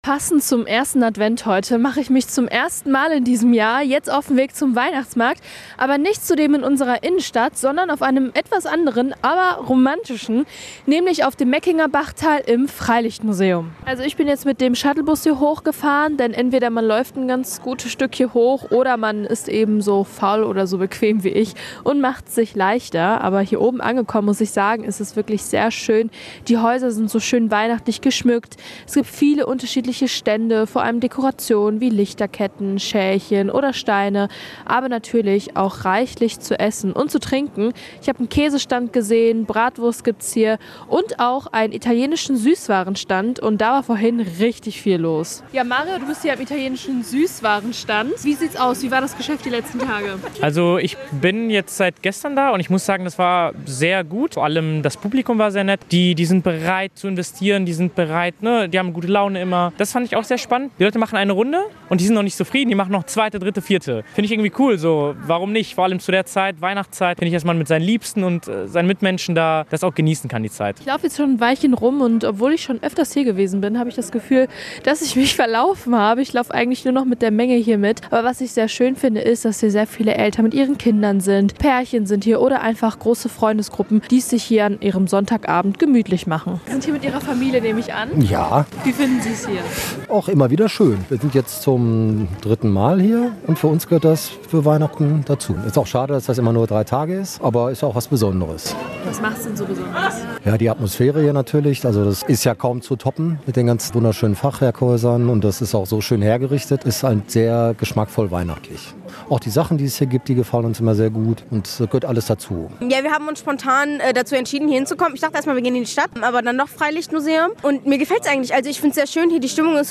reportage-weihnachtsmarkt-flm.mp3